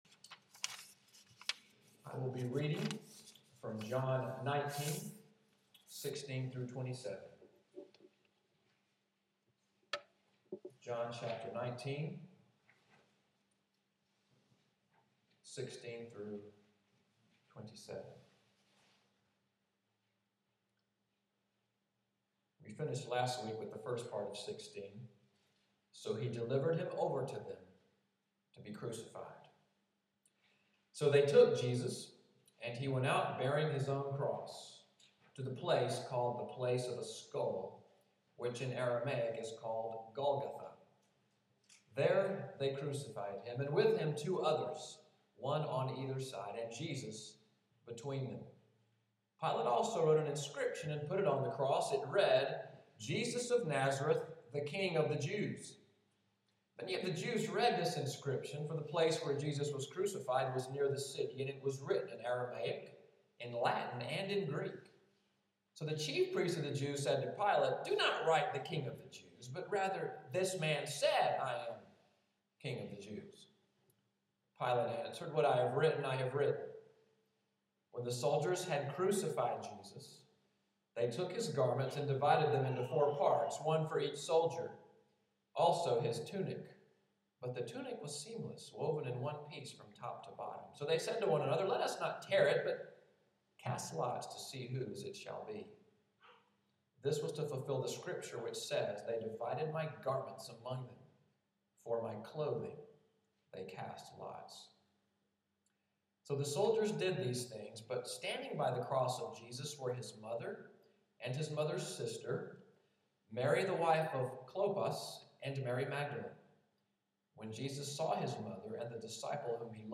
Audio from the sermon, “According to Plan,” March 23, 2014